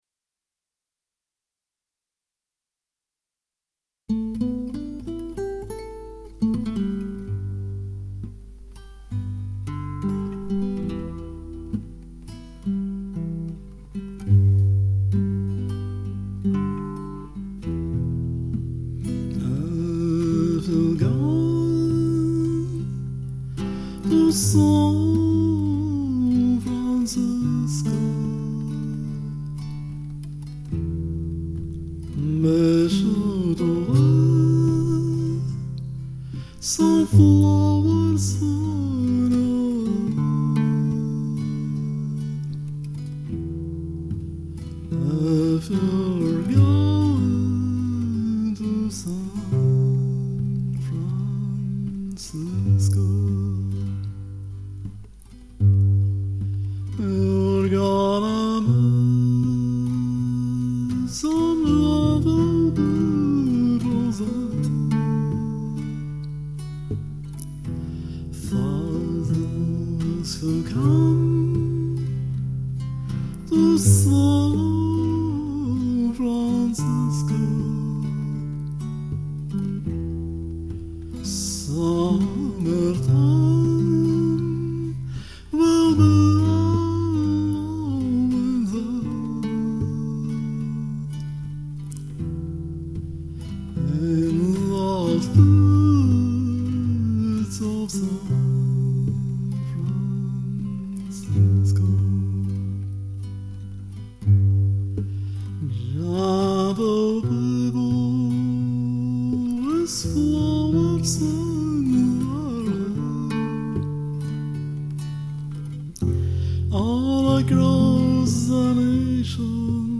• Жанр: Кантри